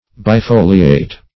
Search Result for " bifoliate" : Wordnet 3.0 ADJECTIVE (1) 1. having two leaves ; The Collaborative International Dictionary of English v.0.48: Bifoliate \Bi*fo"li*ate\, a. [Pref. bi- + foliate.]